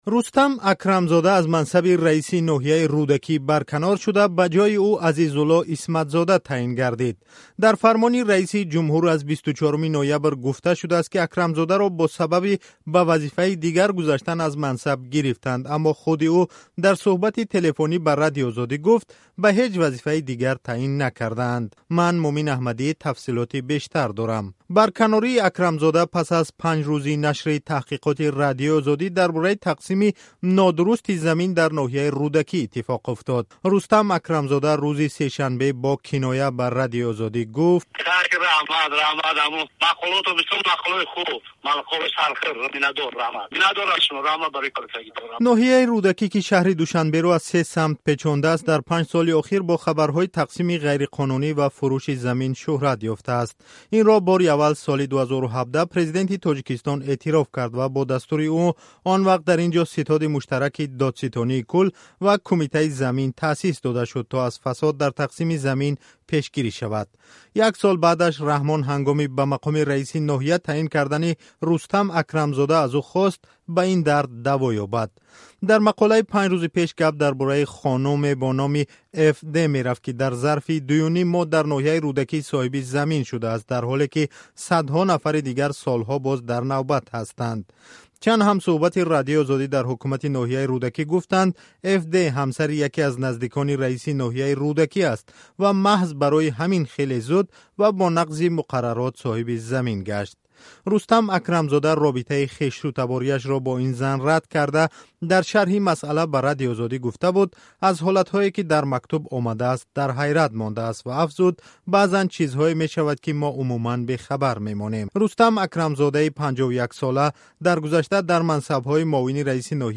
Дар фармони раиси ҷумҳур аз 24-уми ноябр гуфта шудааст, ки Акрамзодаро бо сабаби "ба вазифаи дигар гузаштан" аз мансаб гирифтанд, аммо худи ӯ дар суҳбати телефонӣ бо Радиои Озодӣ гуфт, "ба ҳеч вазифаи дигар таъин накарданд".